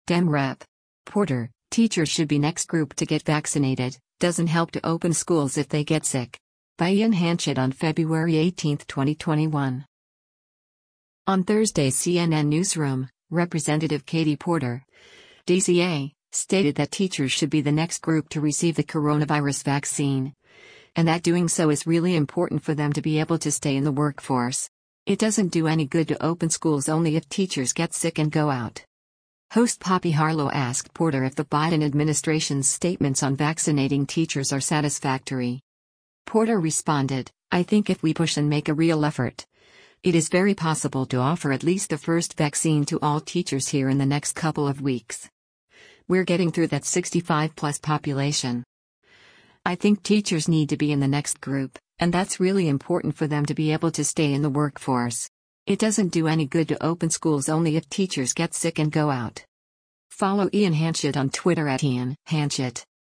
Host Poppy Harlow asked Porter if the Biden administration’s statements on vaccinating teachers are satisfactory.